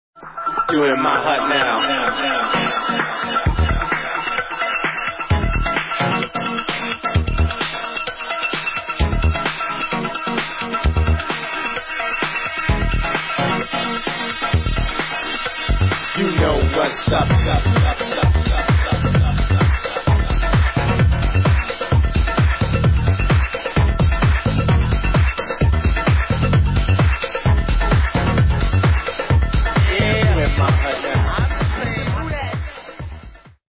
les sonorités de la House 80's sont bien présentes